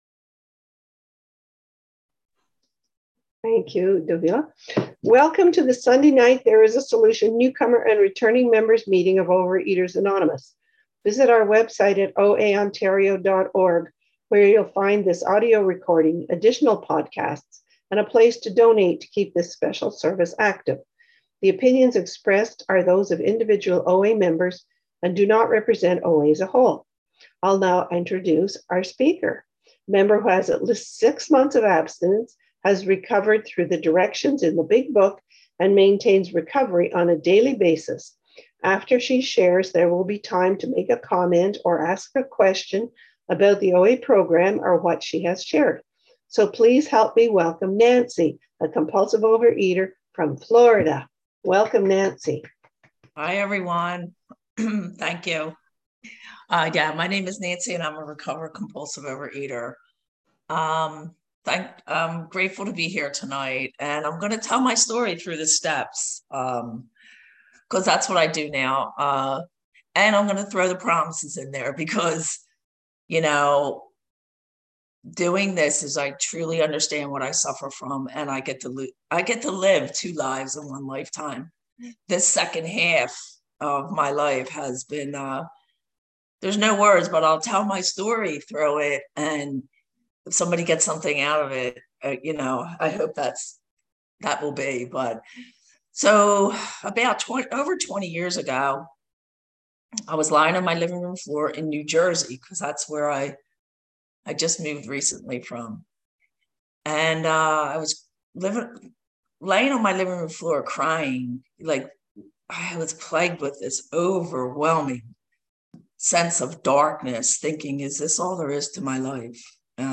Speaker Files OA Newcomer Meeting